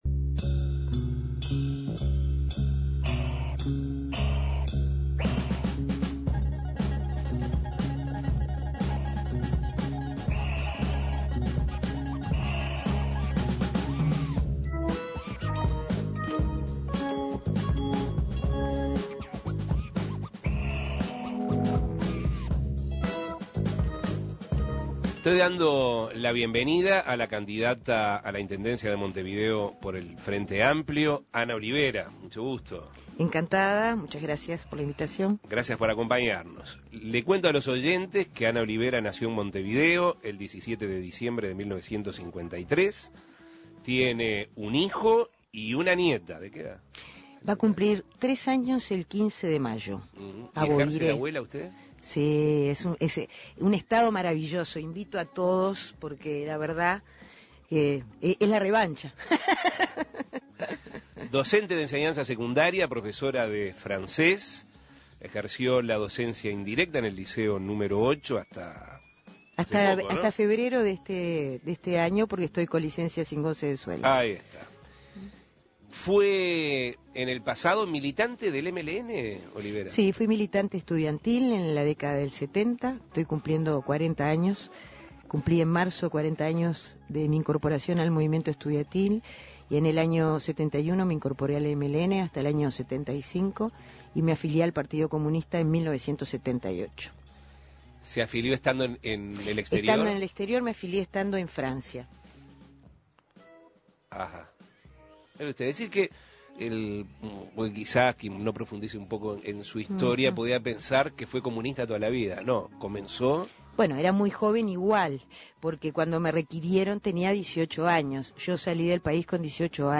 La candidata del Frente Amplio a la Intendencia de Montevideo, Ana Olivera, dialogó sobre el lanzamiento de su campaña, qué asesoramiento recibió, cómo encarará la problemática de los carritos de basura si triunfa en la próxima ronda electoral, qué política aplicaría para la recolección y clasificación de residuos, qué papel desempeñarán los alcaldes en su eventual gestión y el mejoramiento en la relación entre los funcionarios municipales y la ciudadanía, entre otras cosas. Escuche la entrevista.